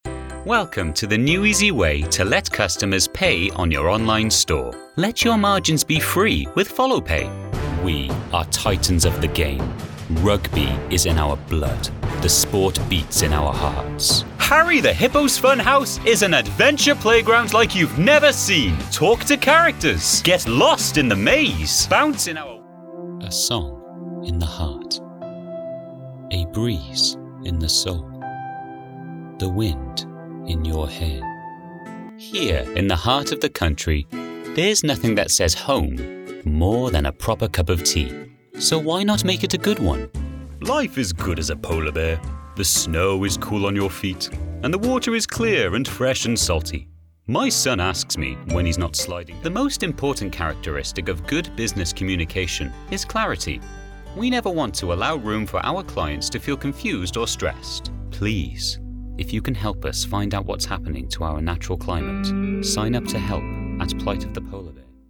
品牌广告2